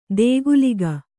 ♪ dēguliga